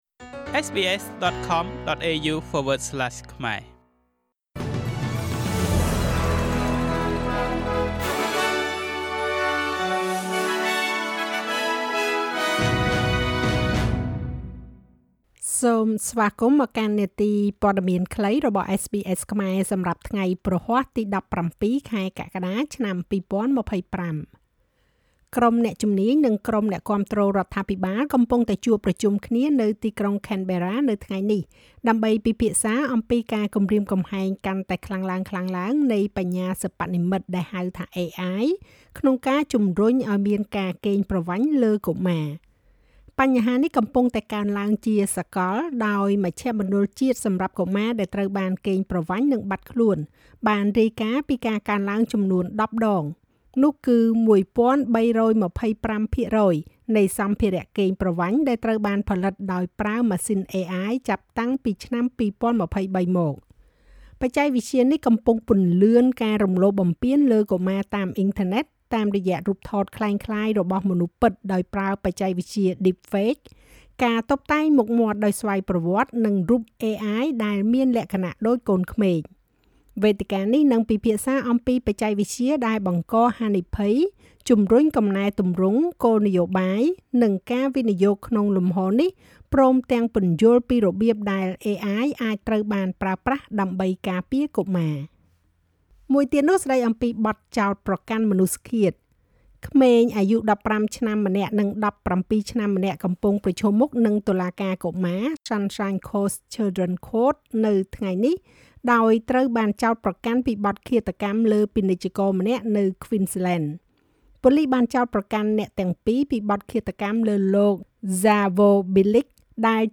នាទីព័ត៌មានខ្លីរបស់SBSខ្មែរ សម្រាប់ថ្ងៃព្រហស្បតិ៍ ទី១៧ ខែកក្កដា ឆ្នាំ២០២៥